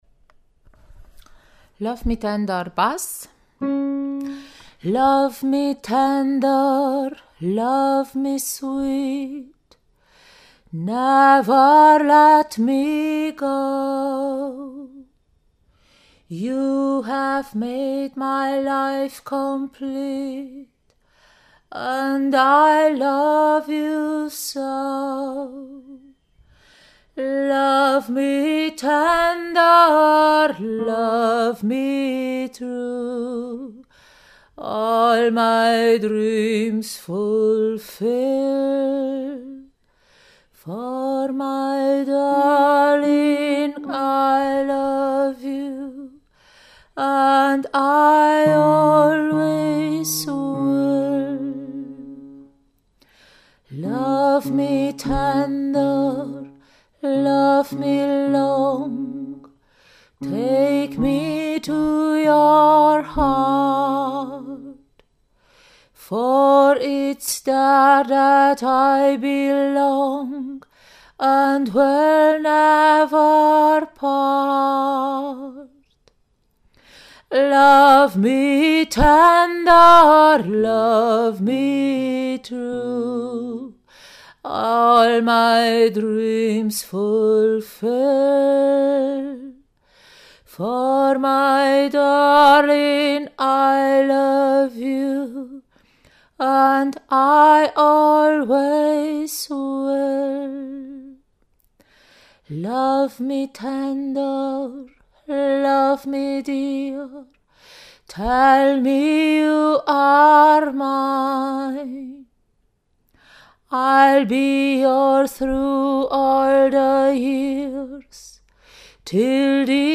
08 - NoNames - ChorArt zwanzigelf - Page 4 | Der moderne Chor in Urbach